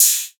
MURDA_HAT_OPEN_KINGZ.wav